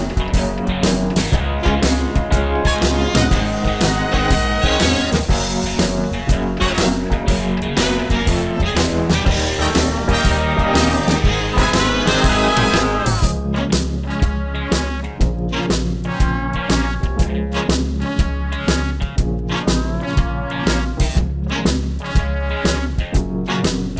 Two Semitones Down Jazz / Swing 3:49 Buy £1.50